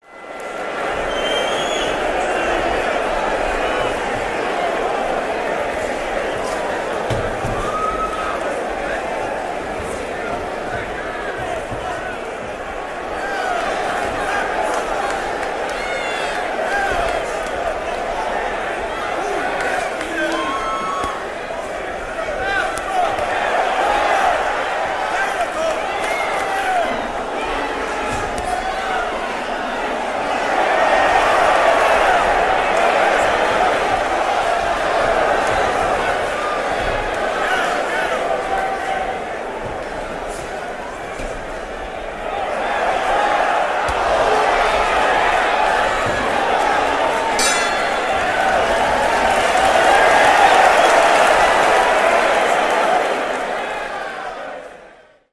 Tiếng Khán giả Reo hò trong Trận đấu Quyền Anh
Thể loại: Đánh nhau, vũ khí
Description: Tiếng Khán giả Reo hò trong Trận đấu Quyền Anh, Boxing Match Croud Ambaince & Sound effects...
tieng-khan-gia-reo-ho-trong-tran-dau-quyen-anh-www_tiengdong_com.mp3